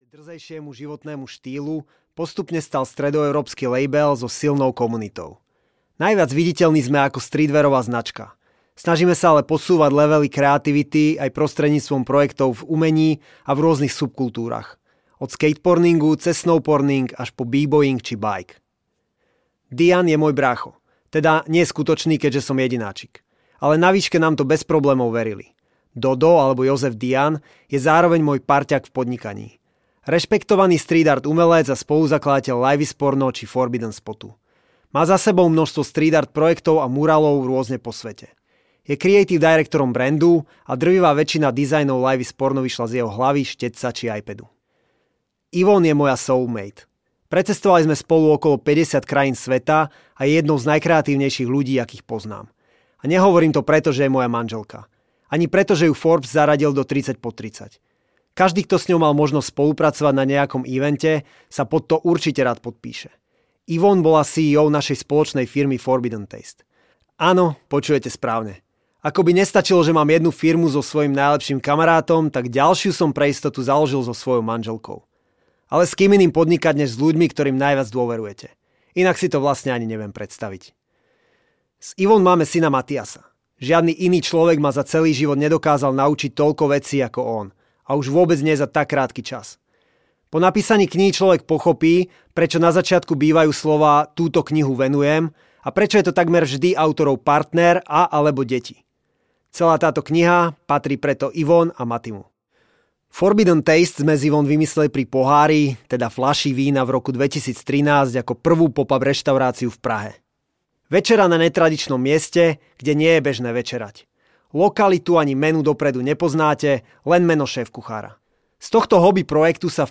FORBIDDEN audiokniha
Ukázka z knihy